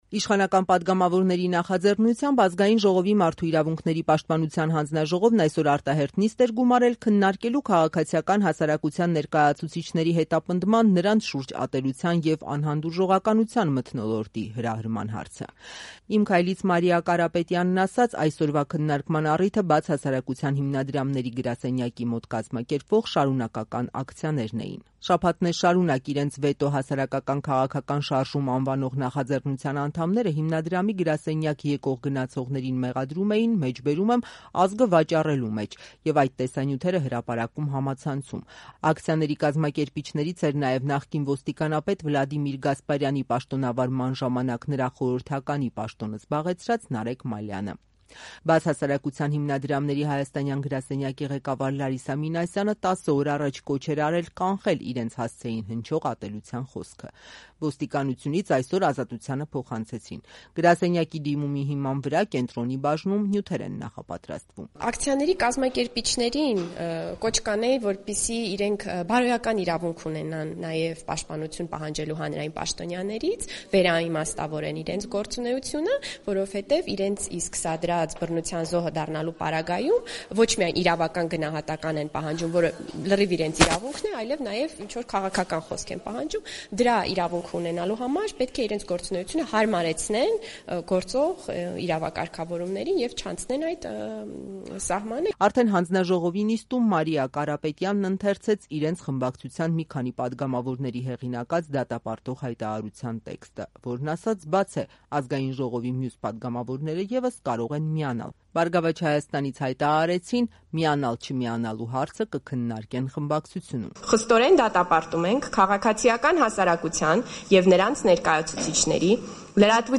«Օրենքը պիտի հստակ քրեական պատժամիջոց սահմանի». քննարկում ՄԻՊ հանձնաժողովում
Ռեպորտաժներ